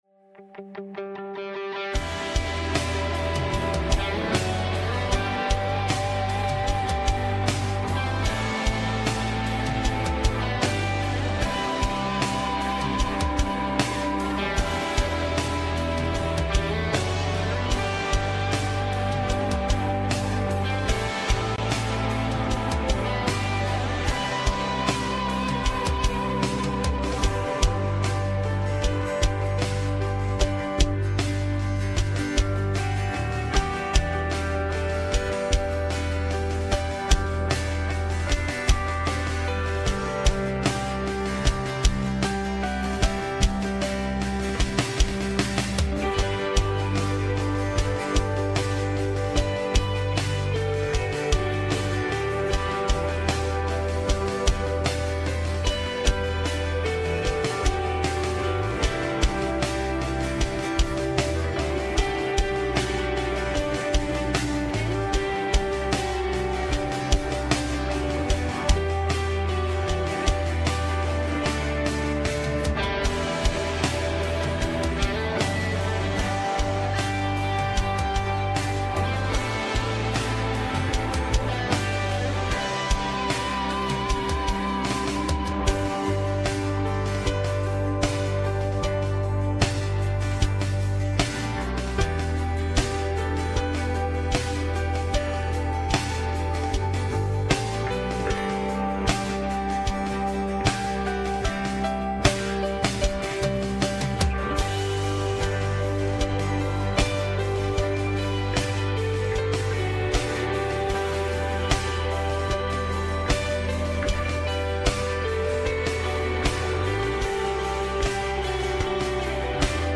Westgate Chapel Sermons C&MA DNA: Christ Our Sanctifier May 25 2025 | 01:29:13 1x 00:00 / 01:29:13 Subscribe Share Apple Podcasts Overcast RSS Feed Share Link Embed